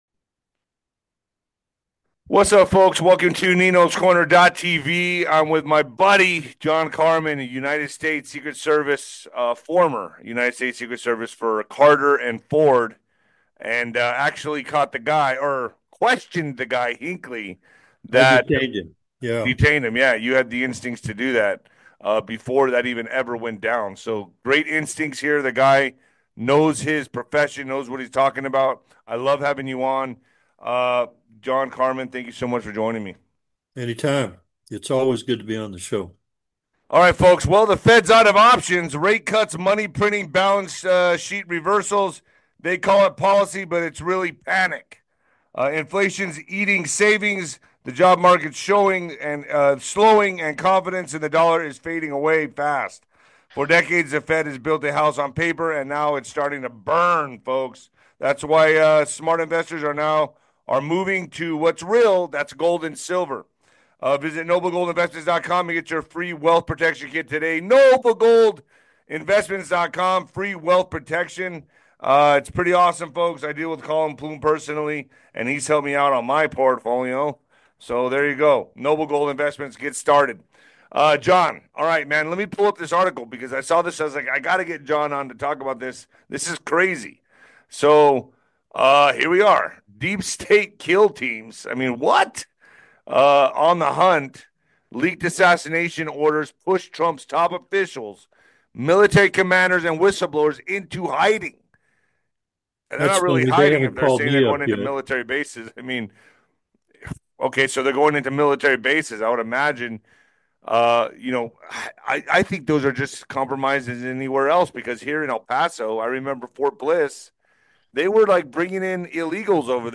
They talk about the current economic situation, suggesting that the Federal Reserve’s policies are causing panic and leading to inflation and a weakening job market.